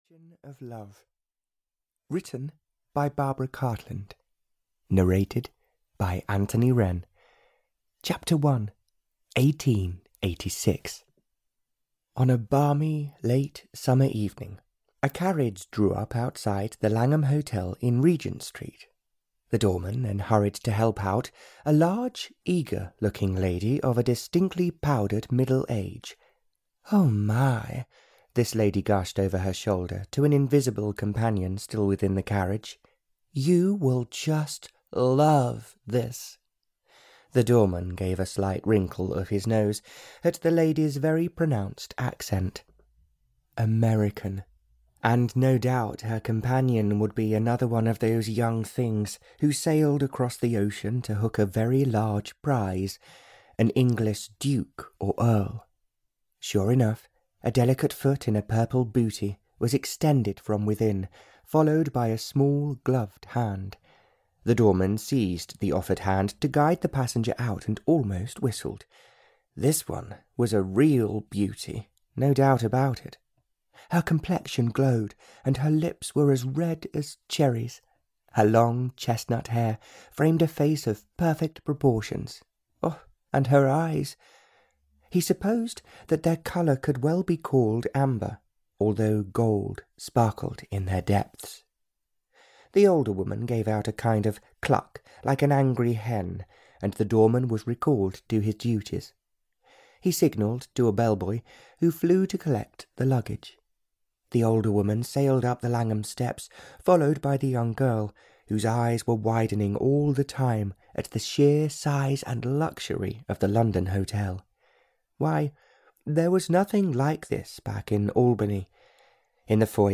An Ocean of Love (EN) audiokniha
Ukázka z knihy